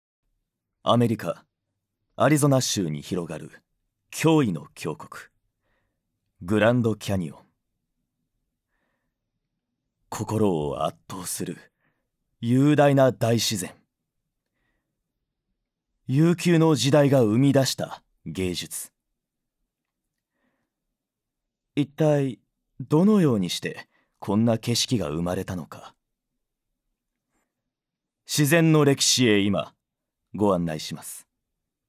ボイスサンプル
●ナレーション②落ち着いた紀行もの